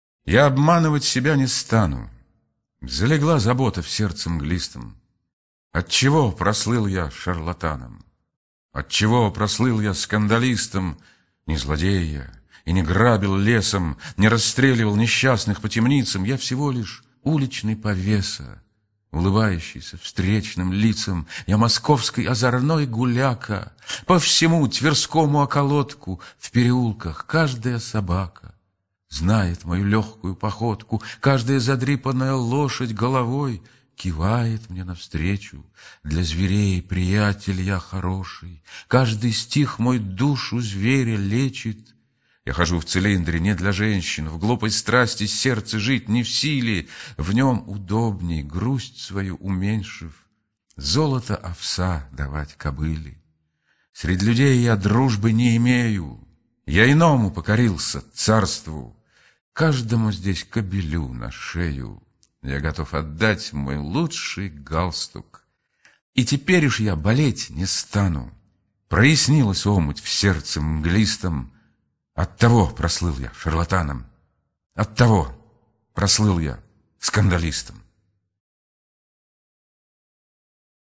Прослушивание аудиозаписи стихотворения «Я обманывать себя не стану...» с сайта «Старое радио».